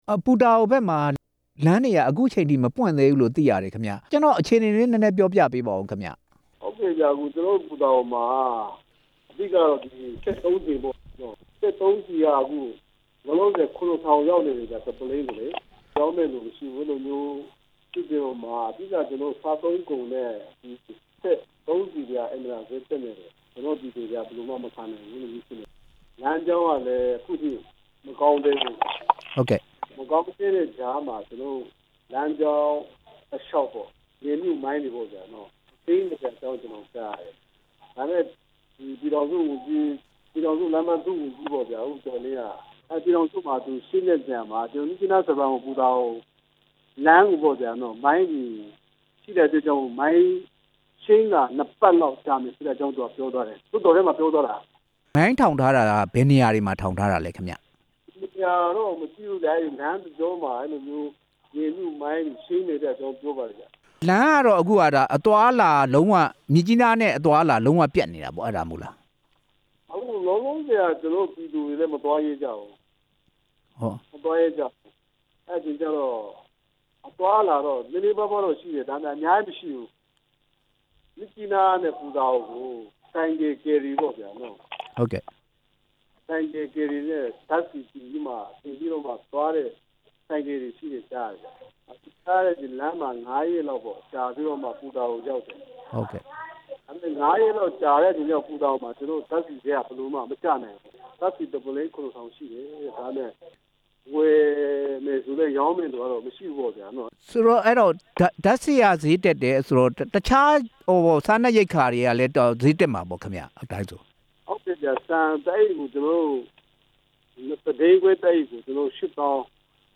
ပူတာအိုမှာ ဓါတ်ဆီဈေး မြင့်တက်နေတဲ့အကြောင်း မေးမြန်းချက်